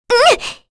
Kirze-Vox_Damage_kr_03.wav